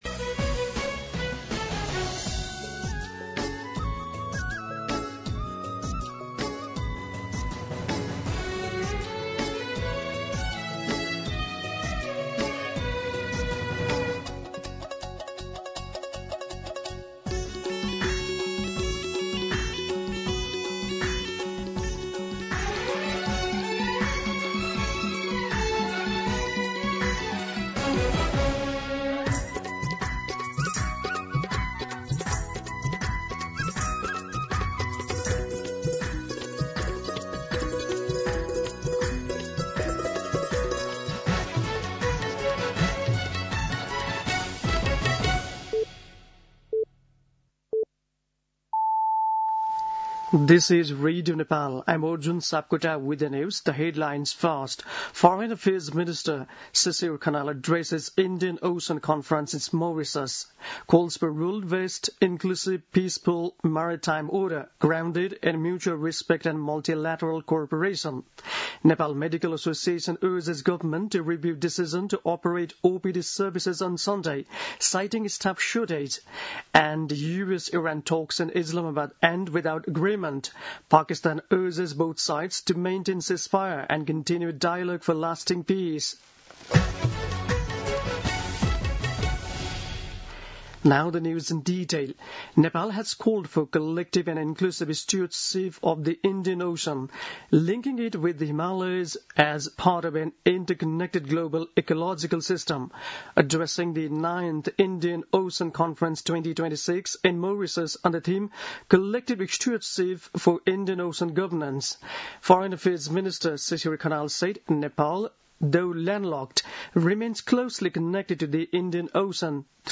दिउँसो २ बजेको अङ्ग्रेजी समाचार : २९ चैत , २०८२
2Pm-English-News-29.mp3